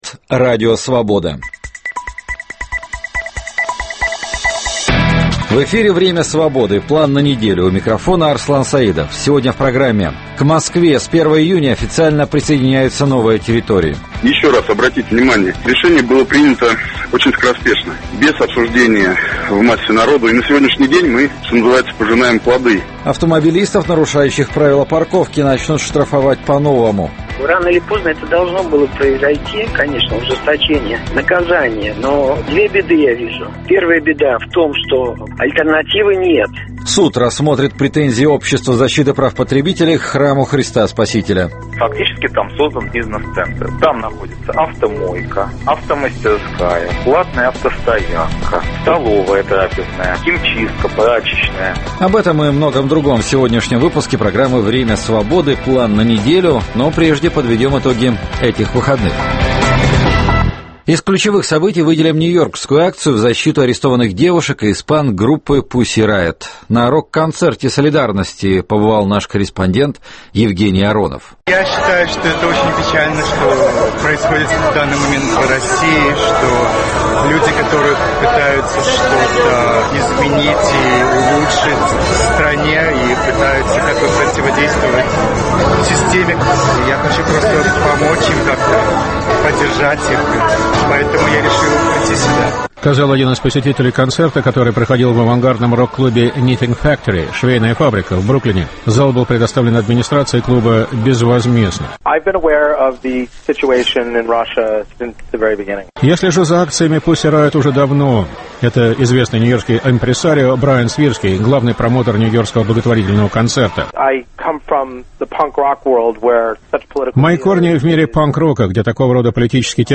Программу попеременно ведут редакторы информационных программ в Москве и Праге.